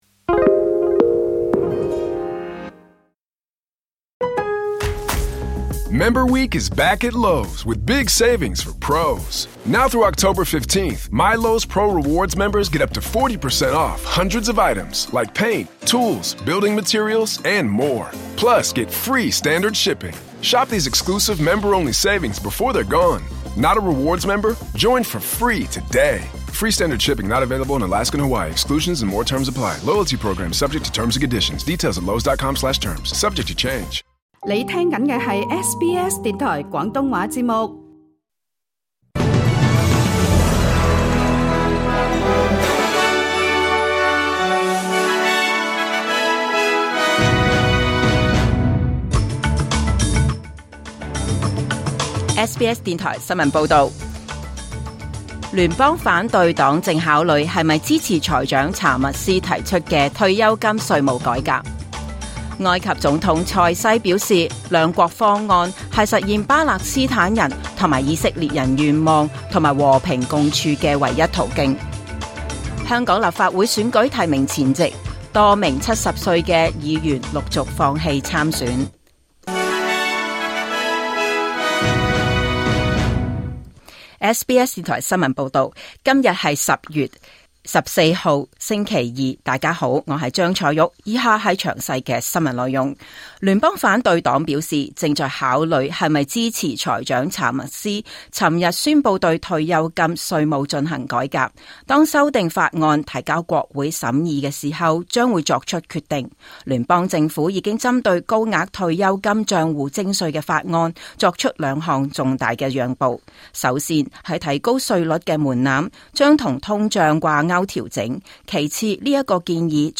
2025 年 10 月 14 日 SBS 廣東話節目詳盡早晨新聞報道。